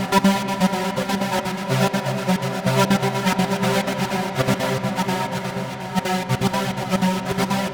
• trancy acid house pad 124 - Cm.wav
trancy_acid_house_pad_124_-_Cm_3y6.wav